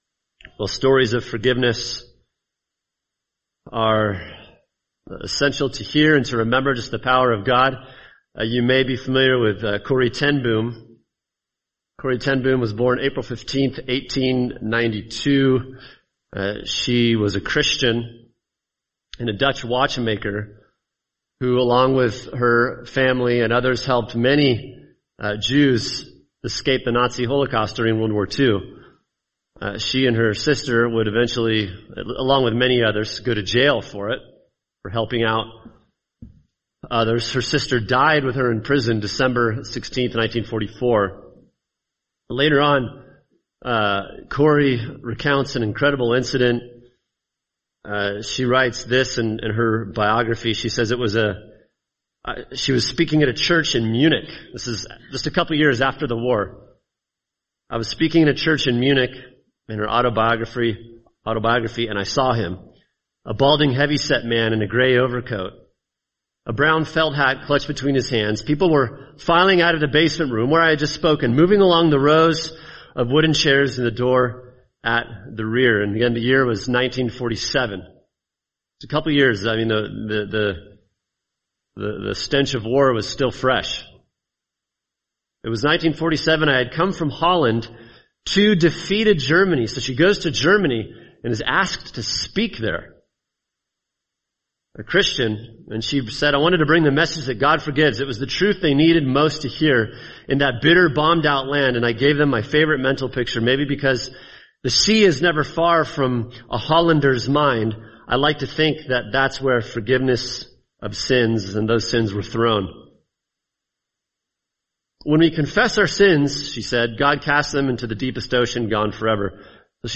[sermon] Philemon 1-7 – Navigating Relationship Hazards, Part 1 | Cornerstone Church - Jackson Hole